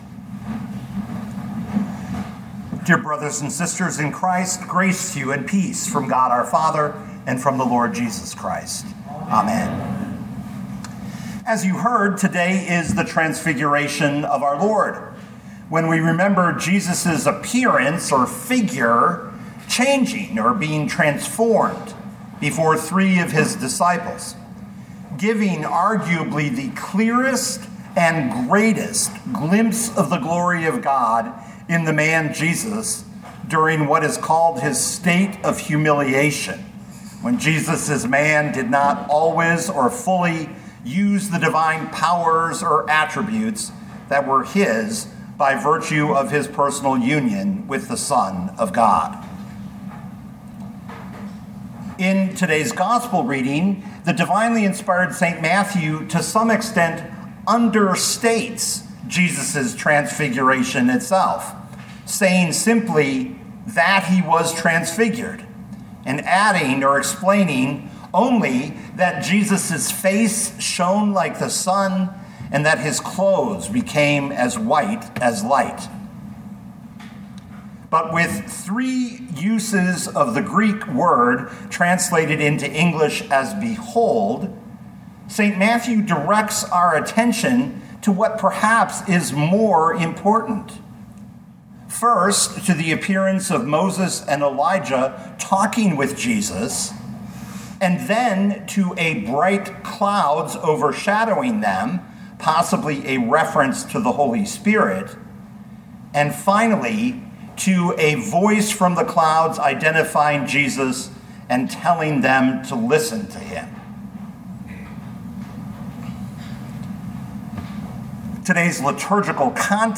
2026 Matthew 17:1-9 Listen to the sermon with the player below, or, download the audio.